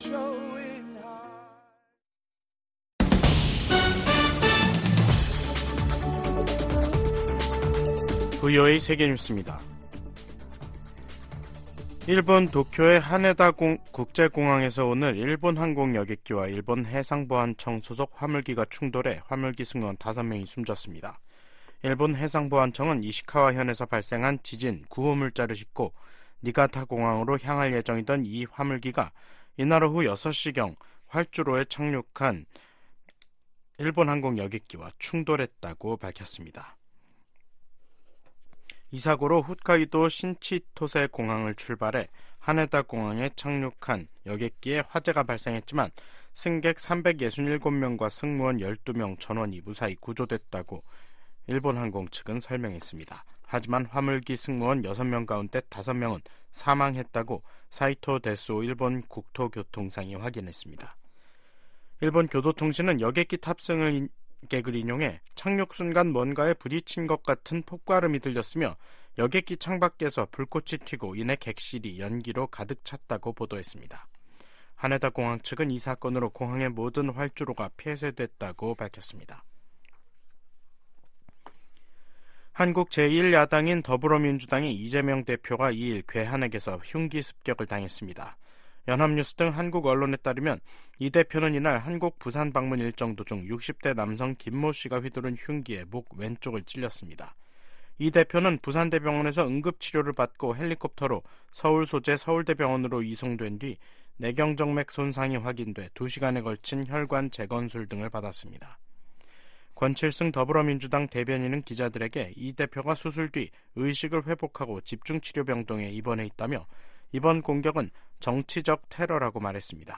VOA 한국어 간판 뉴스 프로그램 '뉴스 투데이', 2024년 1월 2일 3부 방송입니다. 미 국무부가 김정은 북한 국무위원장의 추가 위성 발사 예고에 대륙간탄도미사일(ICBM) 개발과 다름없는 것이라는 입장을 밝혔습니다. 한국이 2년간의 유엔 안전보장이사회 비상임이사국 활동을 시작했습니다. 북한과 러시아가 미국의 금융 제재망을 우회해 무기 거래를 지속할 우려가 있다고 미국 전문가들이 지적했습니다.